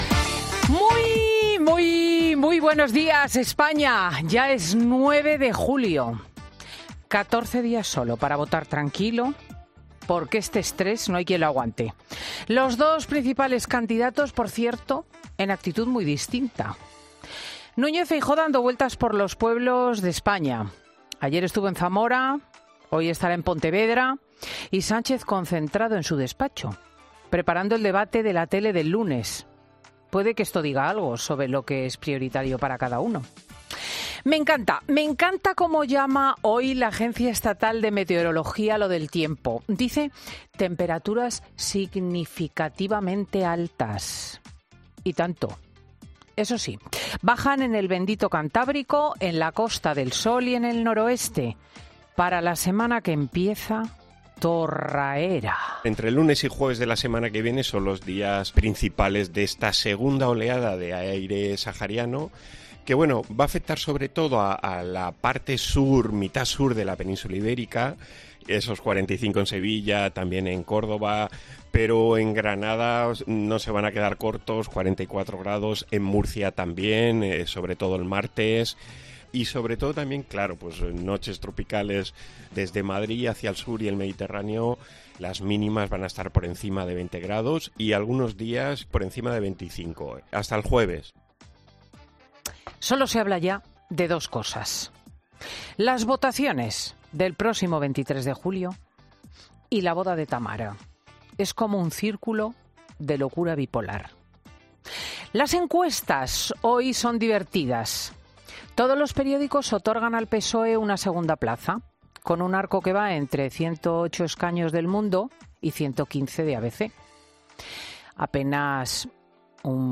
AUDIO: El cara a cara Feijóo-Sánchez o la boda de Tamara Falcó, en el monólogo de Cristina López Schlichting